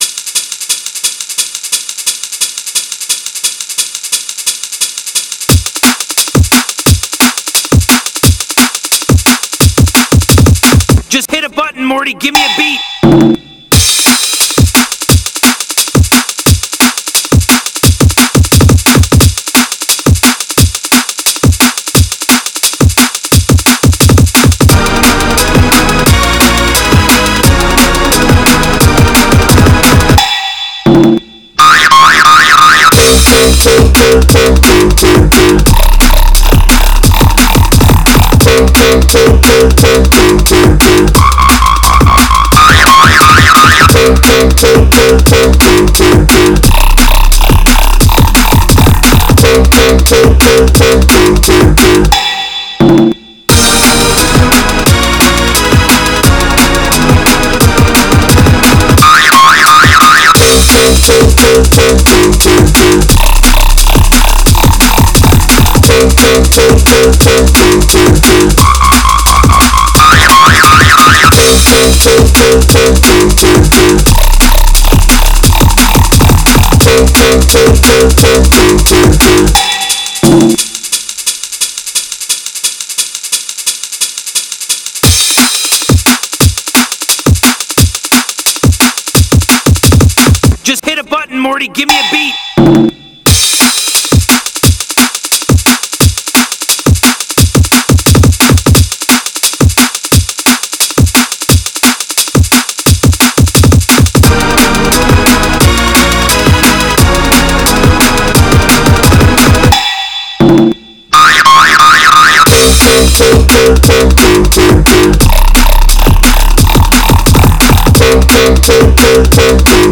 He wanted a Jump Up track with a whomp whomp style and cartoon noises, what do you think?